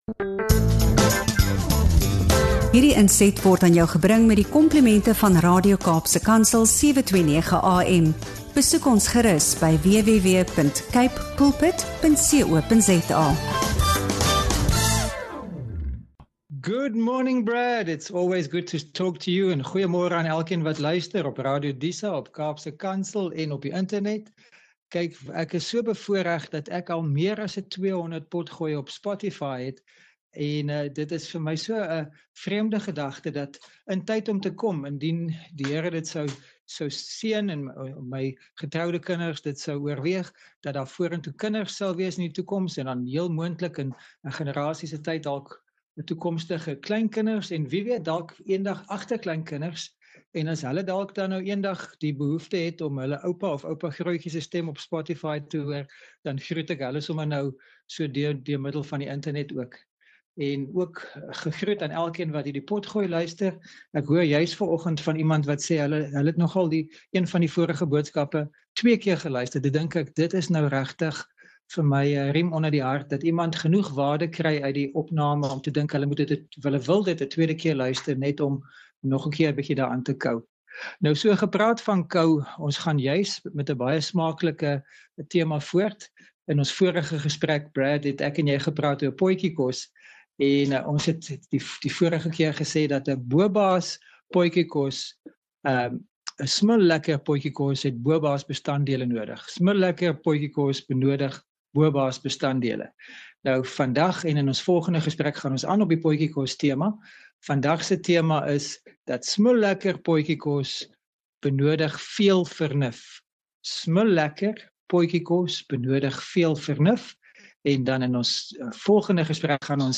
Radio Kaapse Kansel-onderhoud